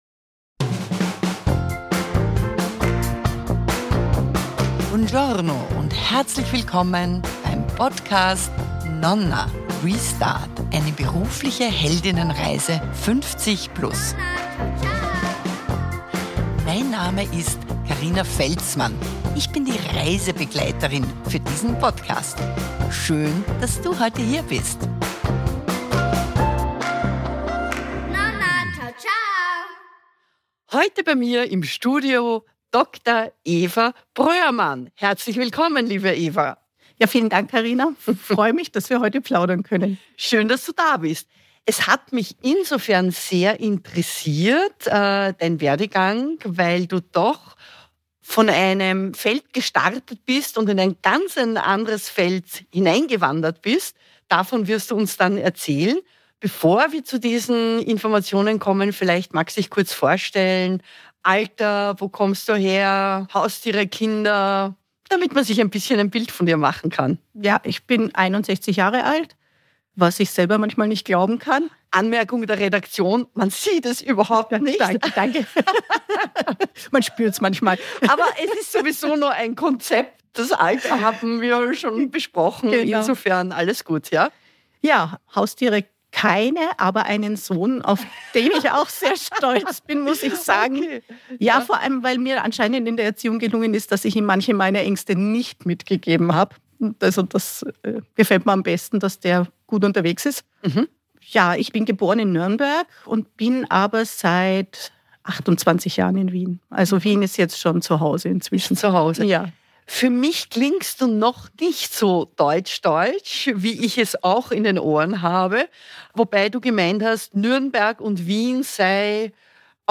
Ein Interview über Neugier, Selbstvertrauen und den Moment, in dem man merkt: Ich bin genau richtig, so wie ich bin.